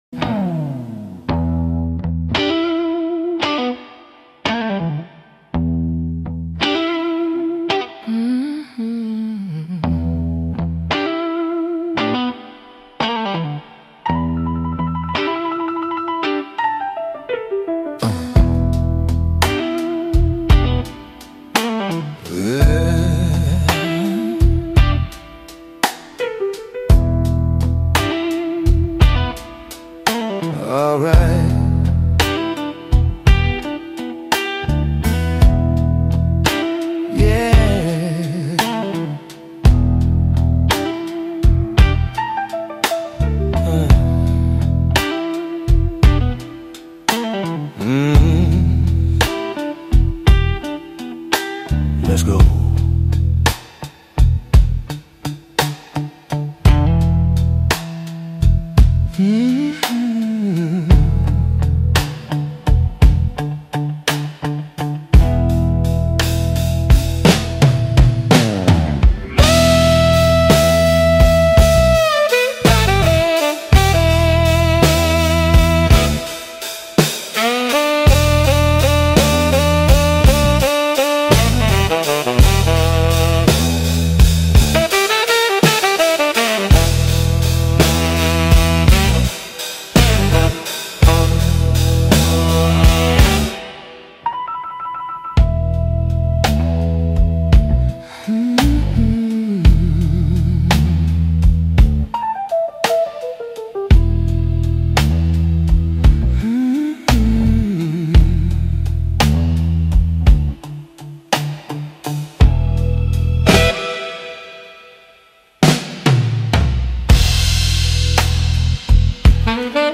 Funk-Grooves-KI-Mix-2026.mp3